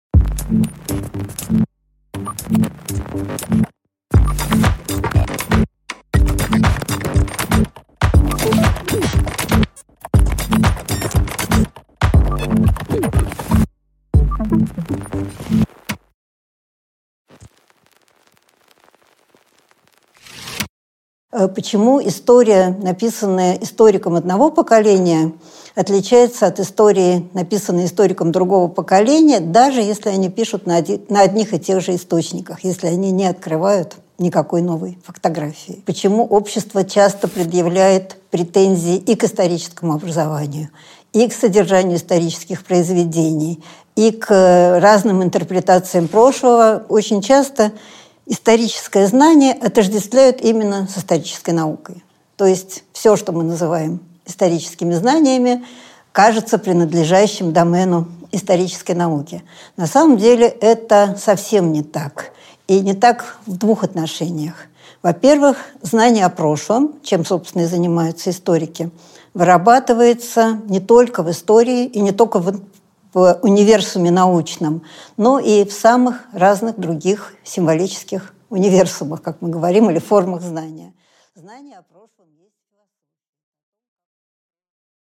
Аудиокнига Дело прошлое | Библиотека аудиокниг
Прослушать и бесплатно скачать фрагмент аудиокниги